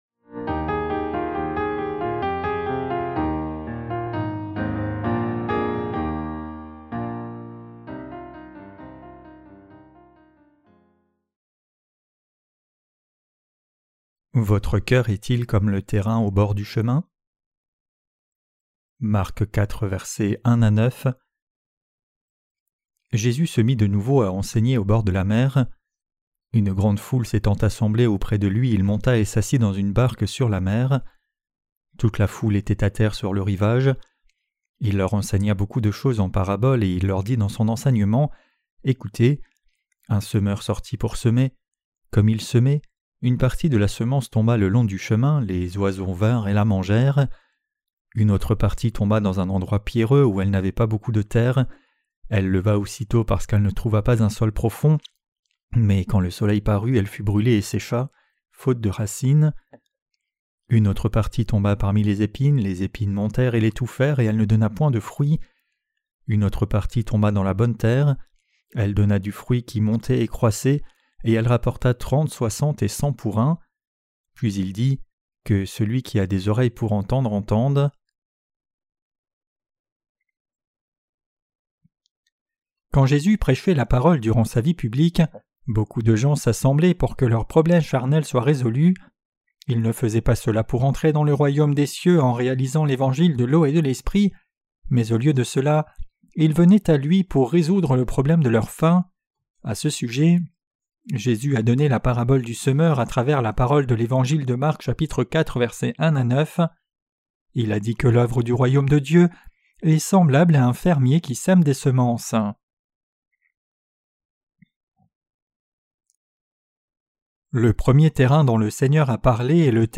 Sermons sur l’Evangile de Marc (Ⅰ) - QUE DEVRIONS-NOUS NOUS EFFORCER DE CROIRE ET PRÊCHER? 9.